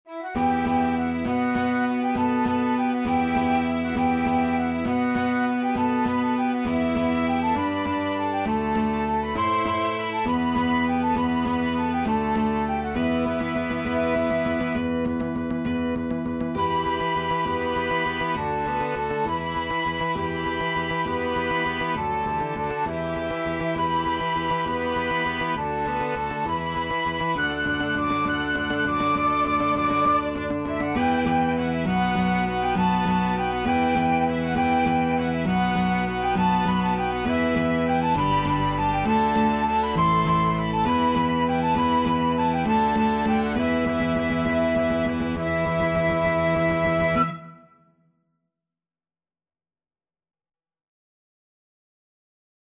Violin
Cello
Guitar (Chords)
Bass Guitar
3/4 (View more 3/4 Music)
Fast and agressive =200